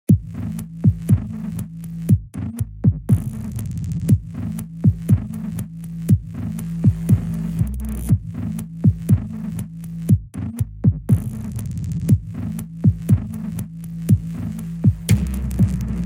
Turbine_120bpm_-_Loop
imma-be-bass.mp3